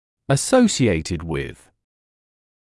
[ə’səusɪeɪtɪd wɪð] [-ʃɪeɪtɪd][э’соусиэйтид уиз] [-шиэйтид]связанный с, сопутствующий (чему-то)